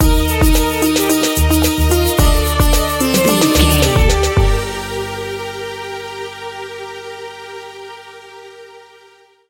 Aeolian/Minor
World Music
percussion
talking drum